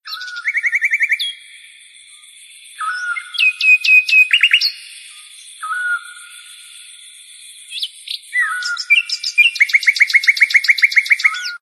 Category: Animal Ringtones